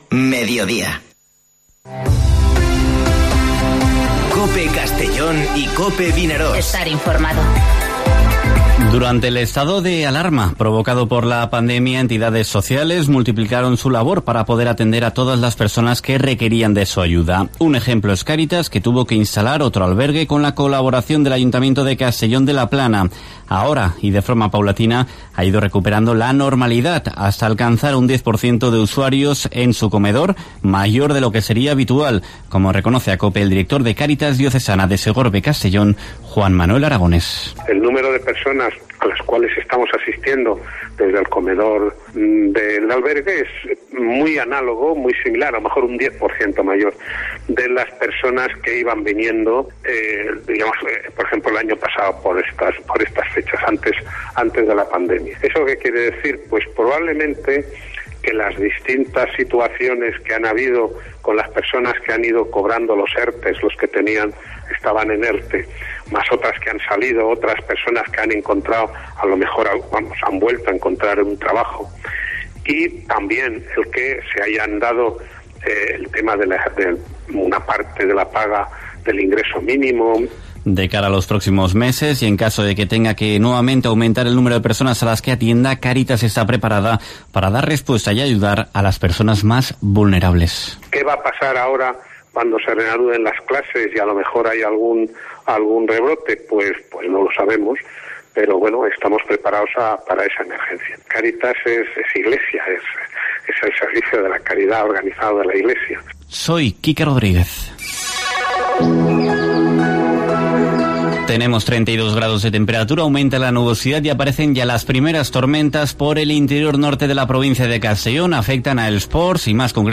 Informativo Mediodía COPE en la provincia de Castellón (12/08/2020)